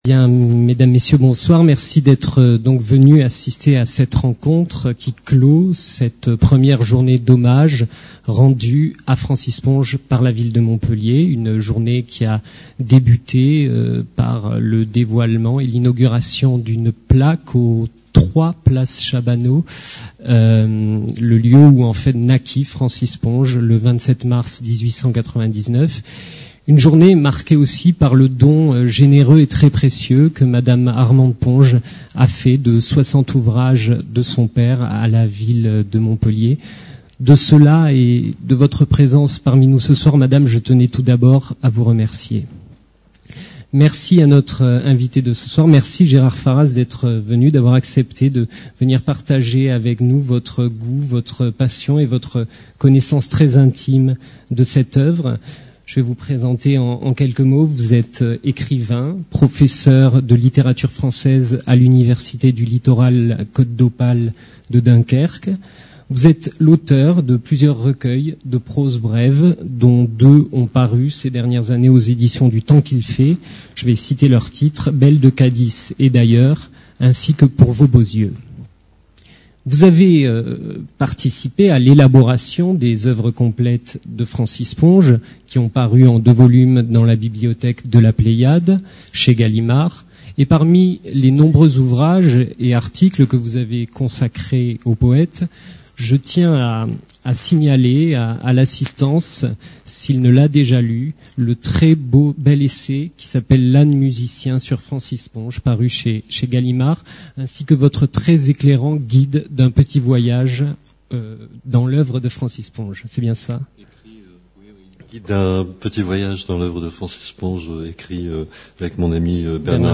Conférences